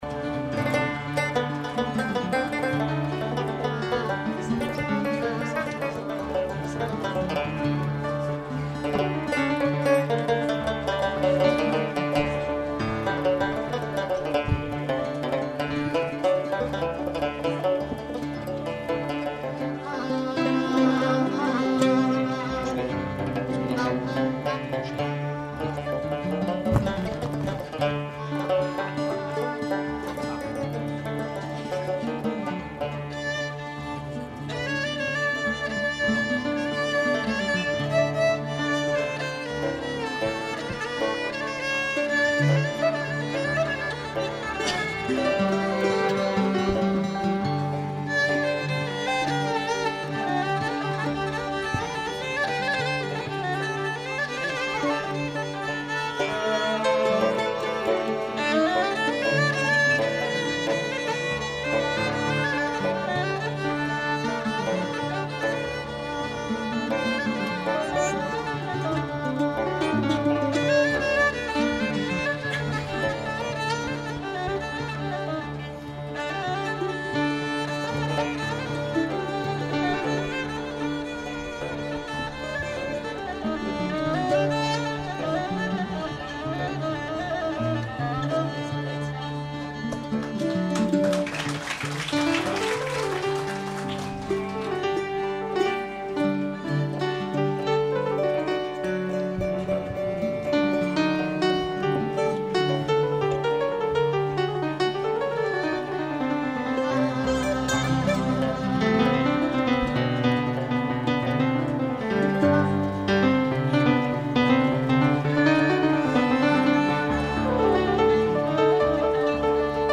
-3- طرب غرناطي